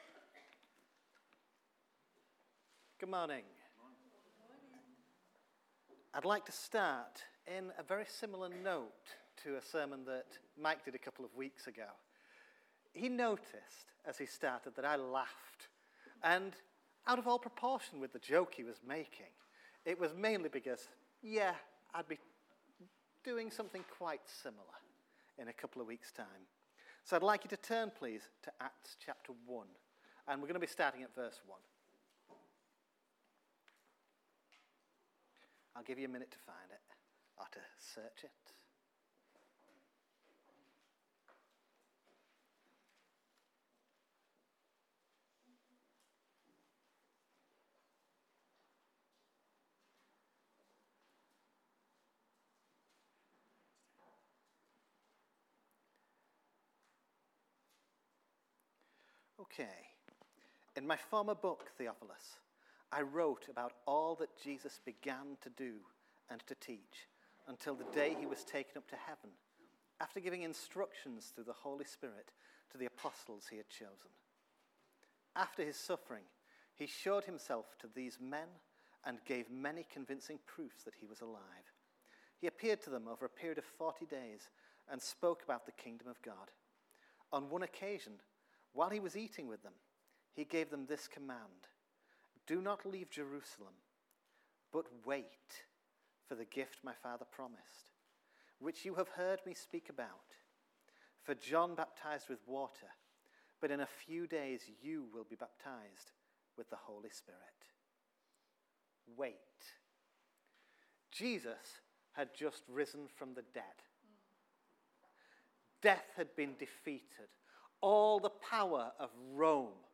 OCF Sermons: 2026-04-26